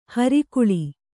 ♪ hari kuḷi